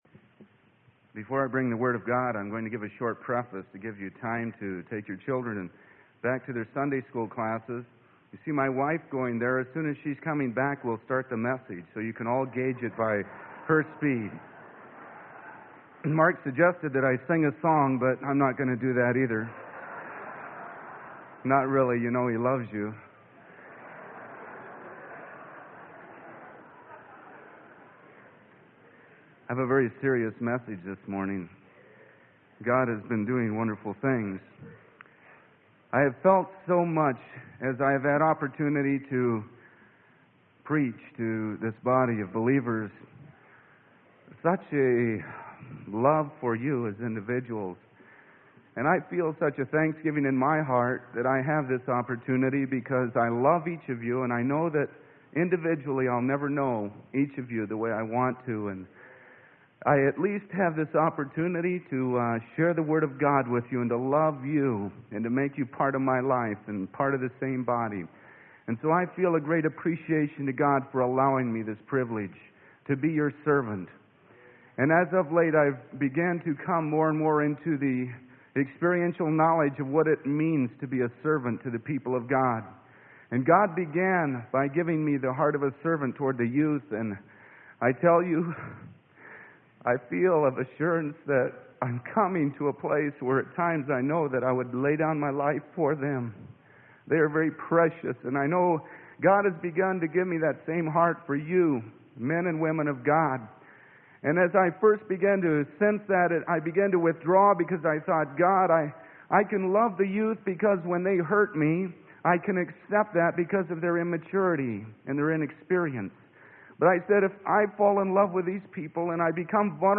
Sermon: ONE WITH GOD (JOHN 17).